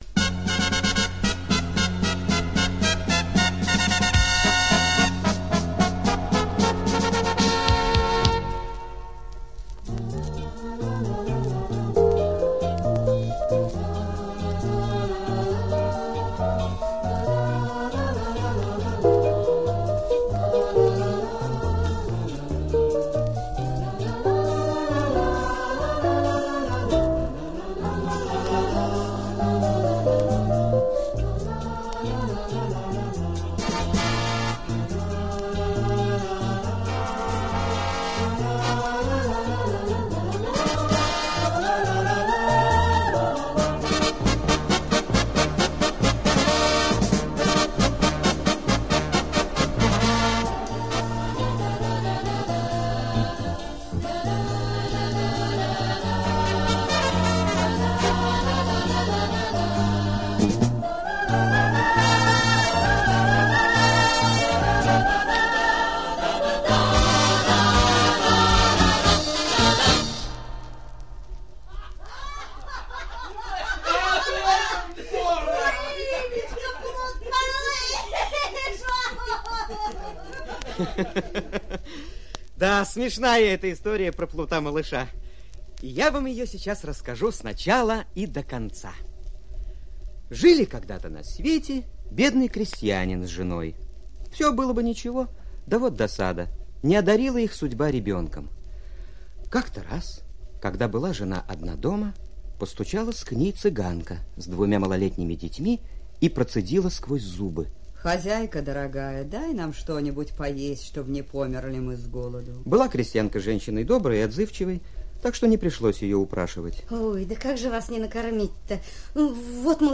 Плут-малыш - французская аудиосказка - слушать онлайн